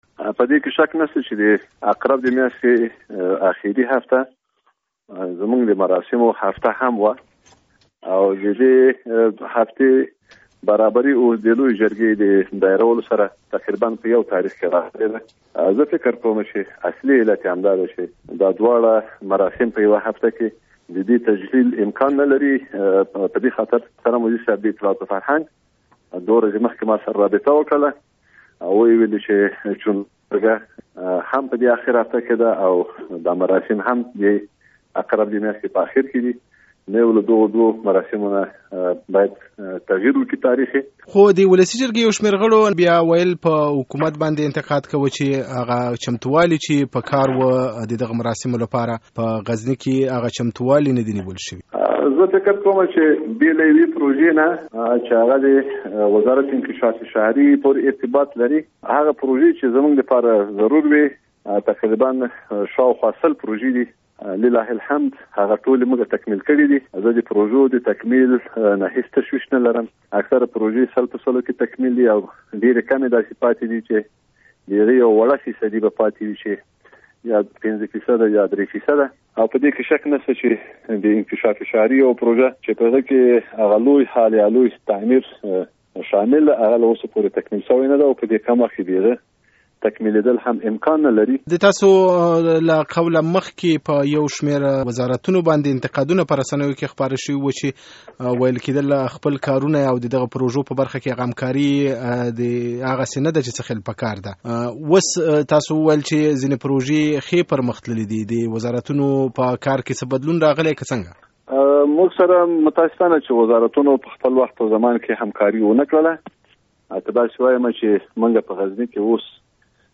له موسی خان اکبر زاده سره مرکه